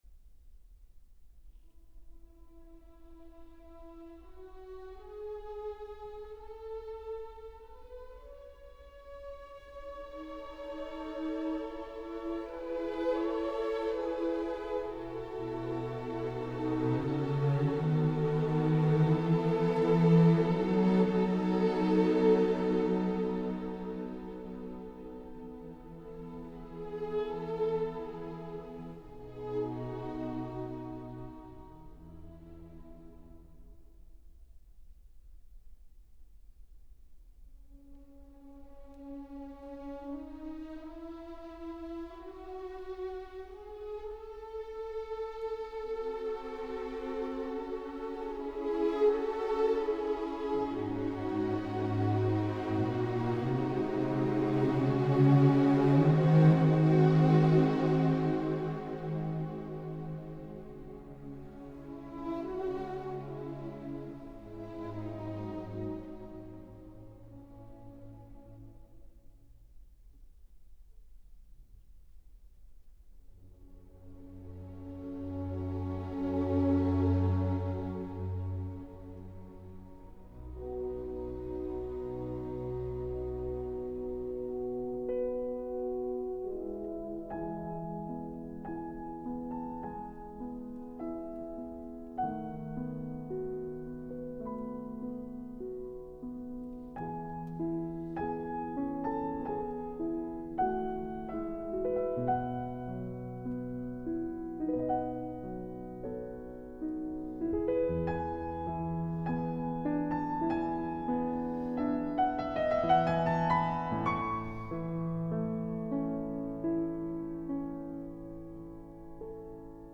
chopin_-piano-concerto-no.1-movement-2-largo-romance-zimerman.m4a